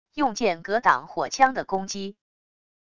用剑格挡火枪的攻击wav音频